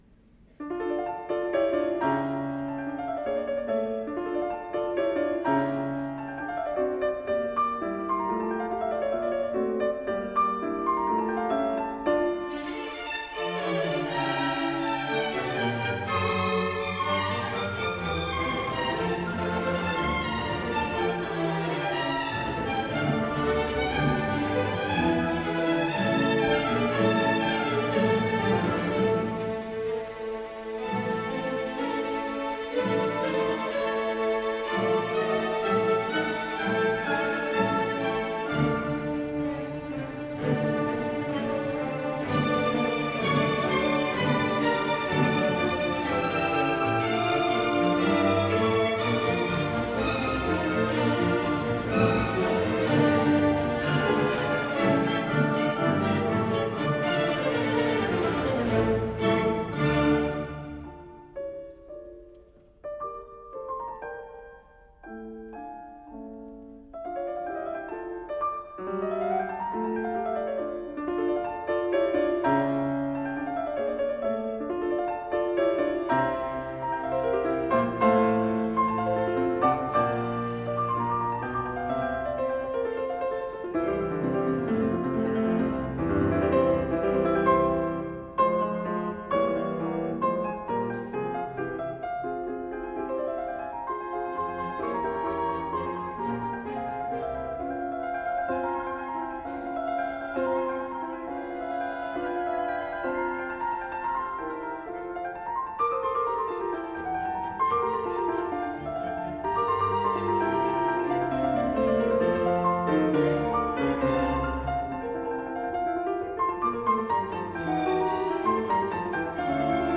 Wolfgang Amadeus Mozart (1756-1791): III Rondo: Allegro assai.